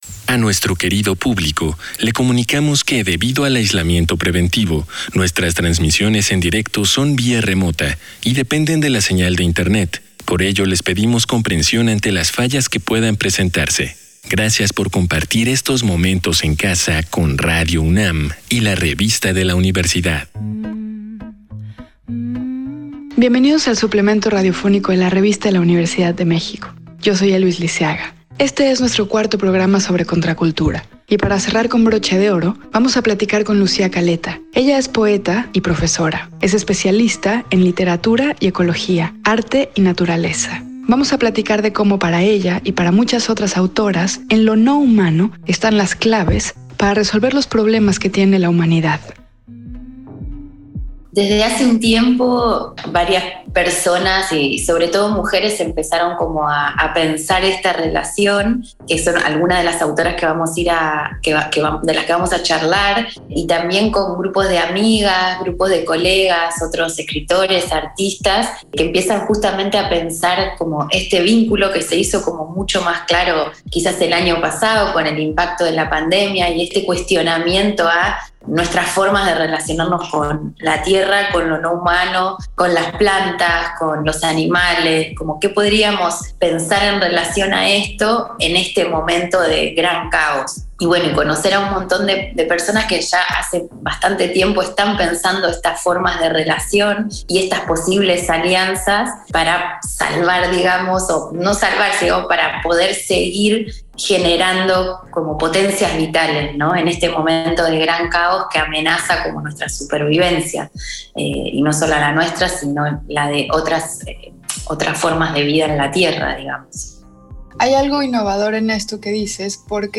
Fue transmitido el jueves 25 de marzo de 2021 por el 96.1 FM.